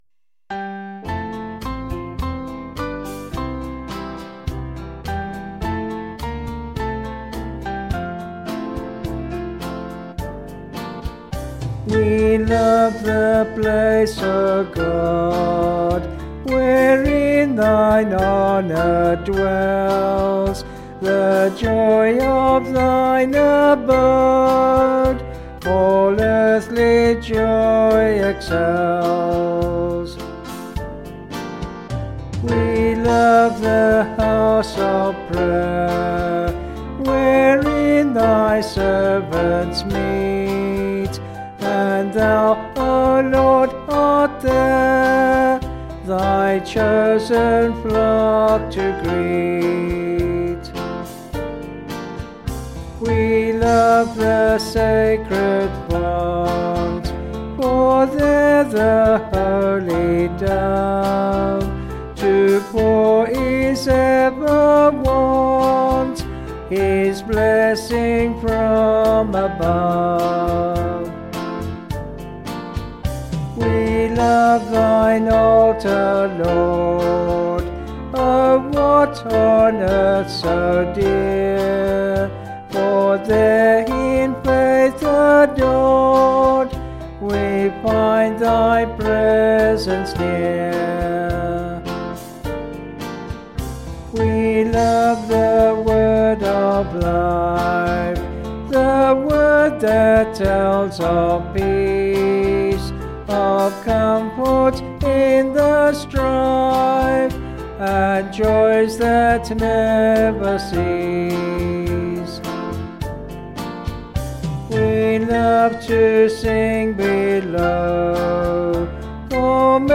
Vocals and Band   264.6kb Sung Lyrics